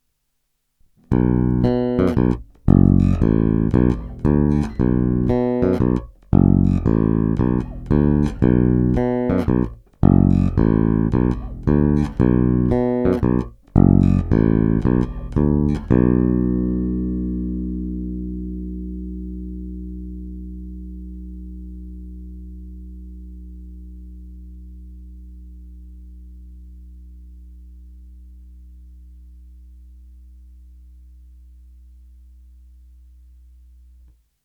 Není-li uvedeno jinak, jsou provedeny rovnou do zvukové karty s korekcemi ve střední poloze, dále jen normalizovány, jinak ponechány bez postprocesingu.
Kobylkový snímač